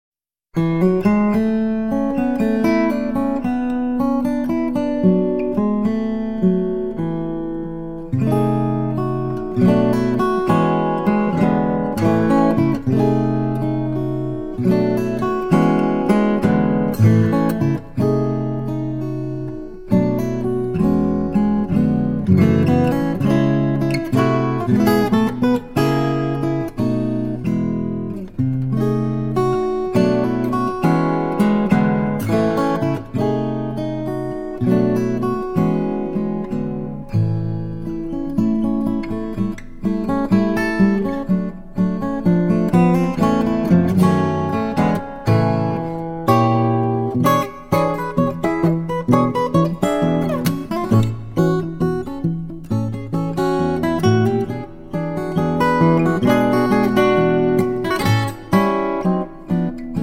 fingerstyle instrumentals
Guitar